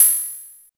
Closed Hats
Wu-RZA-Hat 1.wav